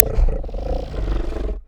chimera_idle_3.ogg